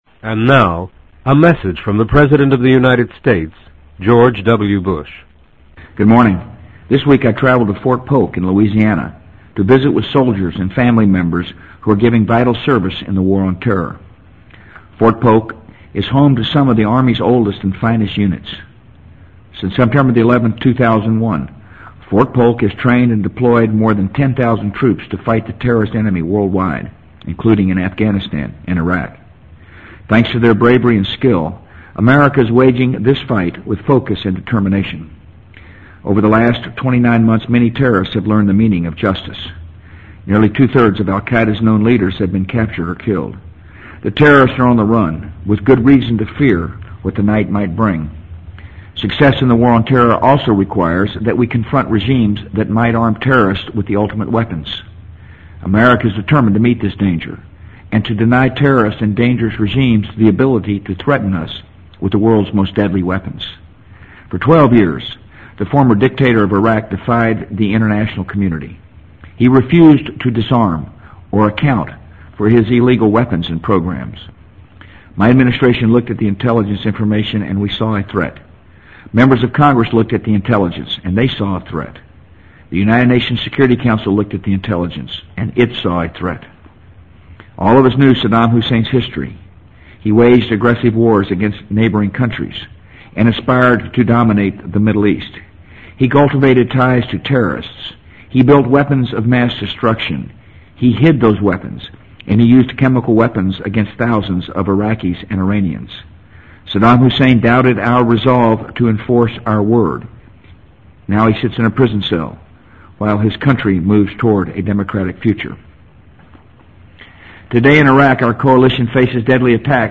【美国总统George W. Bush电台演讲】2004-02-21 听力文件下载—在线英语听力室